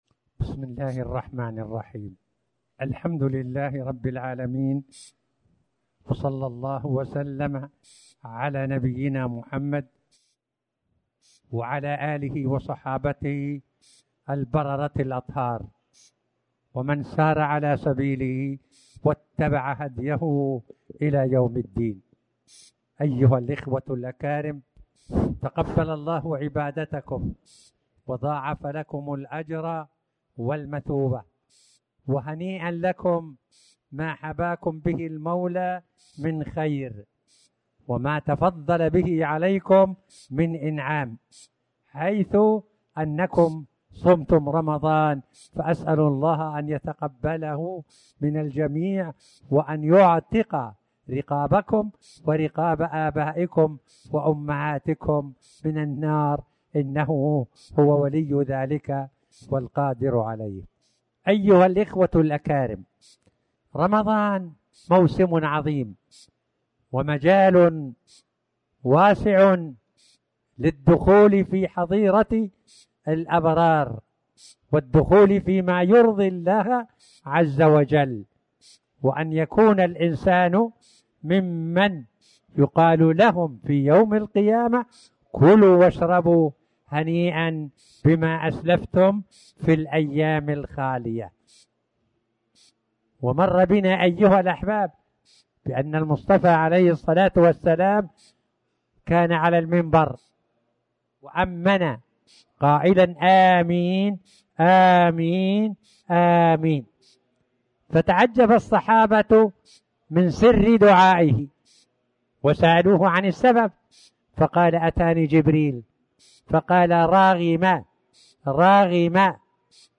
تاريخ النشر ١٠ شوال ١٤٣٩ هـ المكان: المسجد الحرام الشيخ